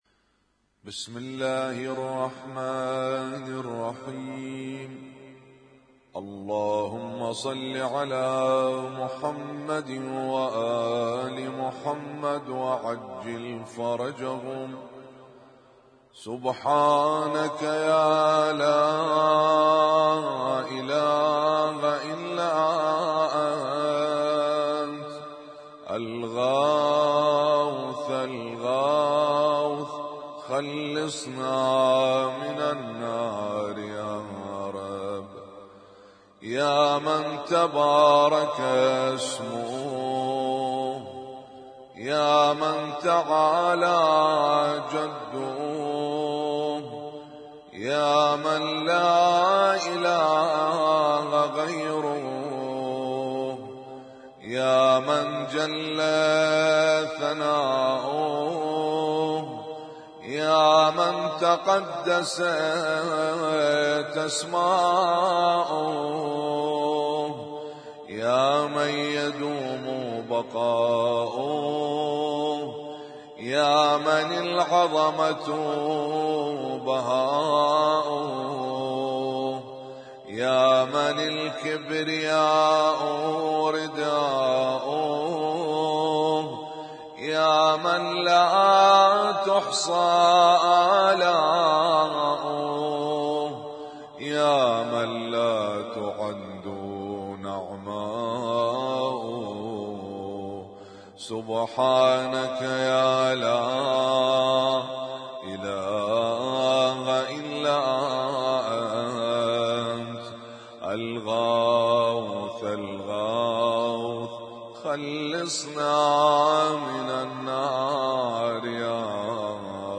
اسم التصنيف: المـكتبة الصــوتيه >> الادعية >> الادعية المتنوعة